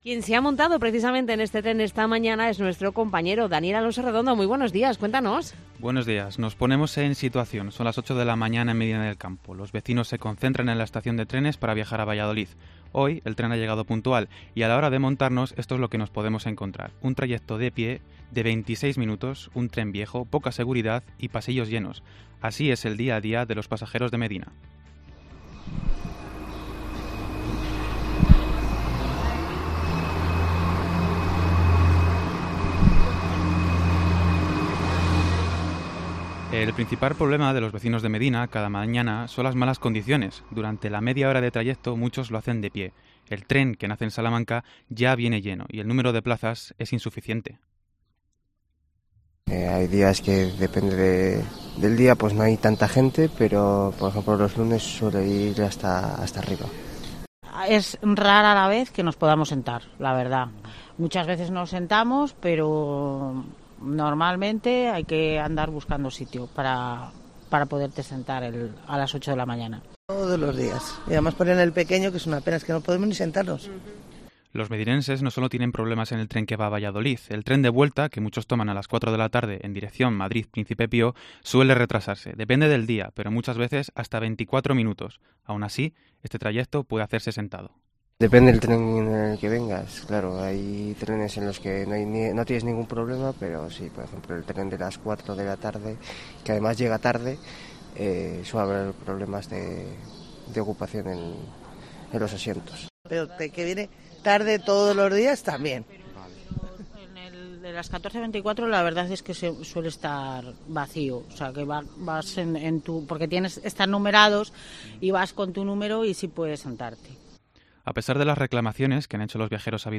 COPE Valladolid se sube al tren para conocer las demandas de los viajeros de la provincia que se desplazan a diario por estudios y trabajo